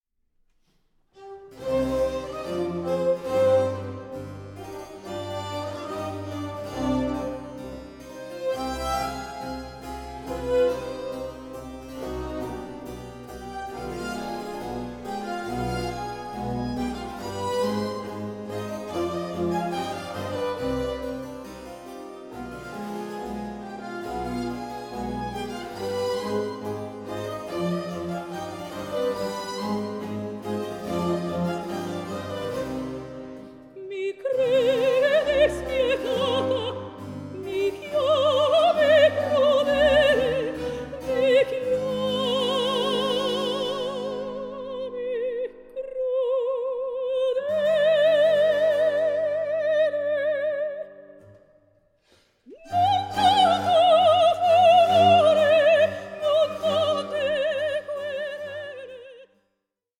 Aria of Ciro